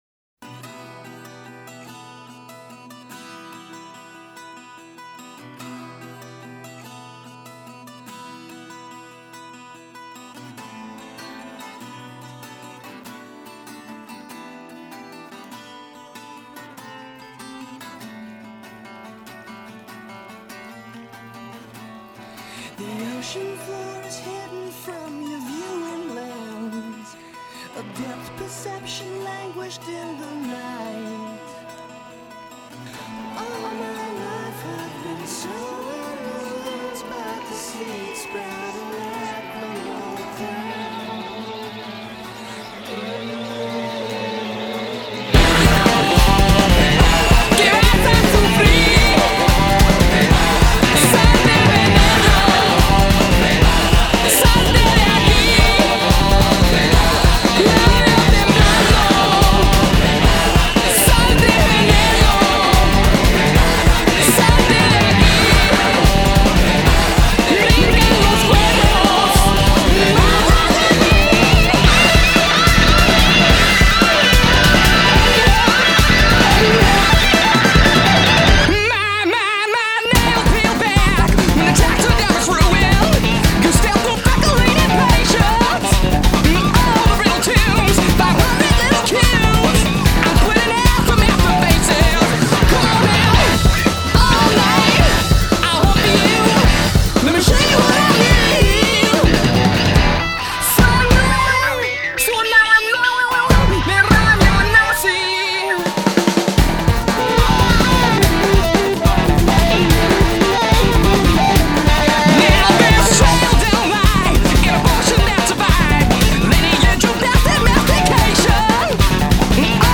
[Download] The music plays in direct correlation with my levels of excitement throughout the night.